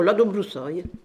Localisation Saint-Hilaire-des-Loges
Catégorie Locution